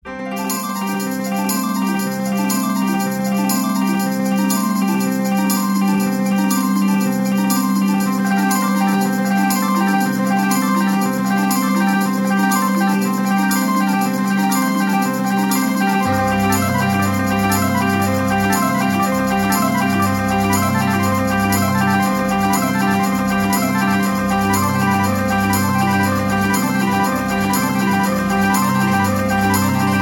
is another little repeated keyboard track